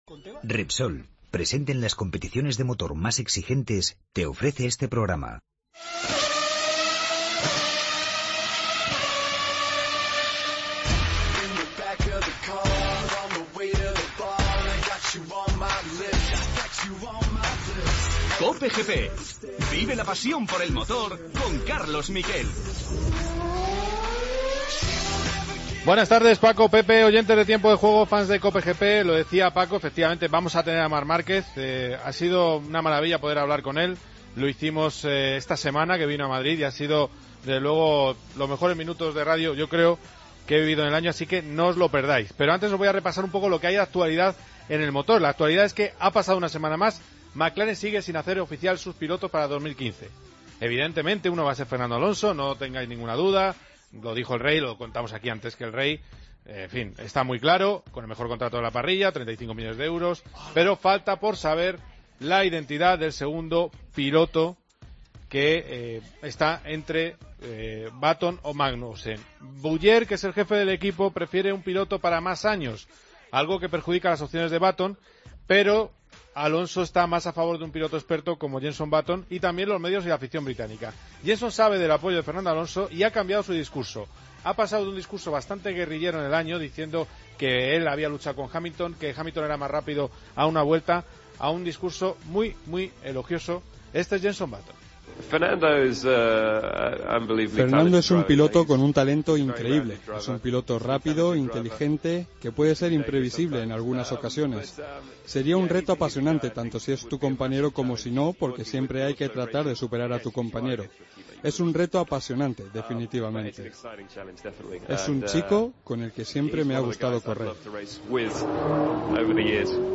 Entrevistamos a Marc Márquez. Contamos las últimas novedades del contrato de Fernando Alonso con McLaren.